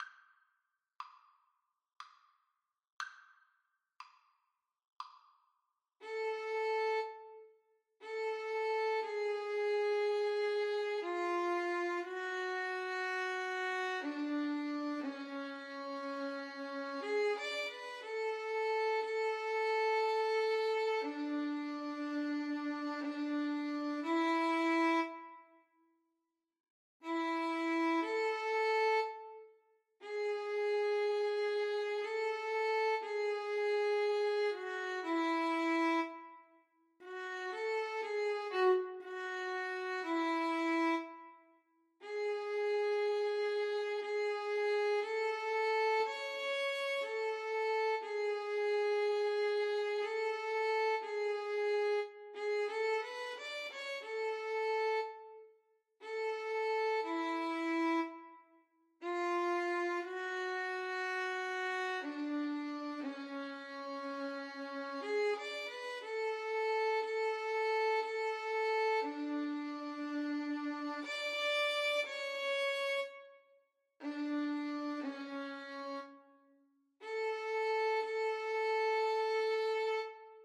Violin 1Violin 2
3/4 (View more 3/4 Music)
Adagio non troppo
Classical (View more Classical Violin Duet Music)